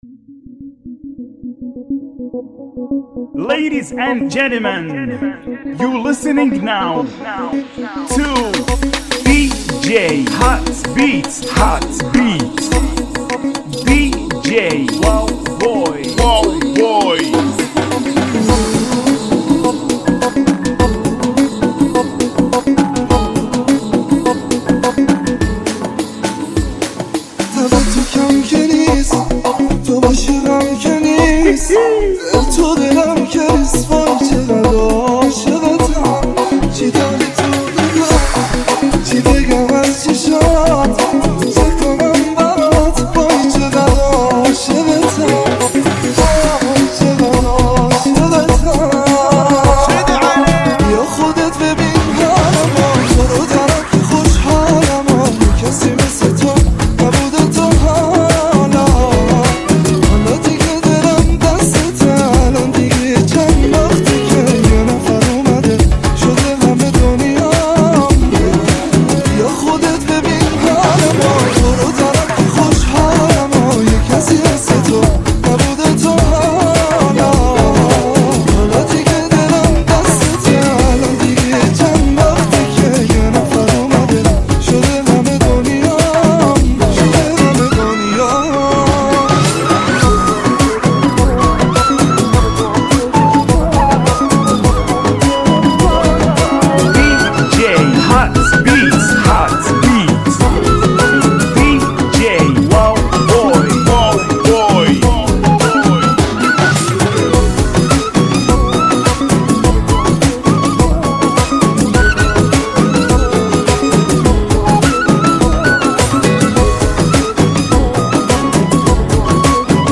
ريمكس اغنيه ايرانيه